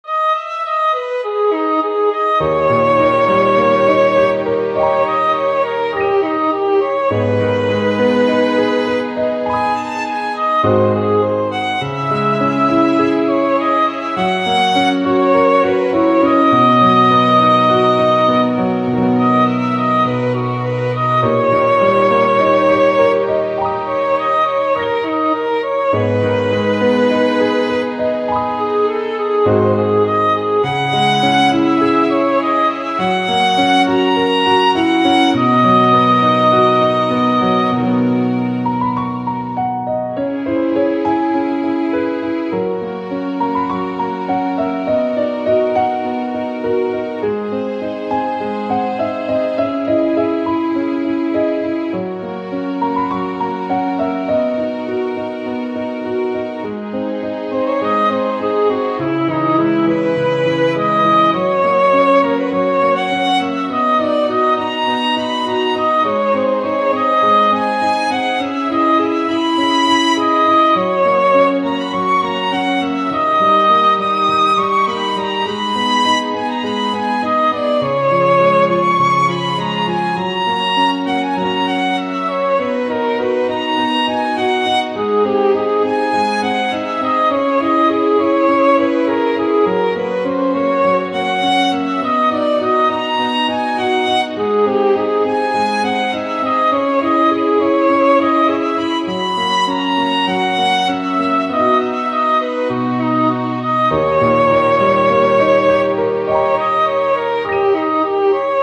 -oggをループ化-   しっとり 切ない 2:32 mp3